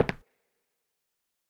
content/hifi-public/sounds/Footsteps/Wood2Right.wav at f5a04026fc8591c53cf9c5357bef5512c6f47b51
Wood2Right.wav